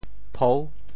簿 部首拼音 部首 竹 总笔划 19 部外笔划 13 普通话 bù bó 潮州发音 潮州 pou6 文 中文解释 薄 <名> (形声。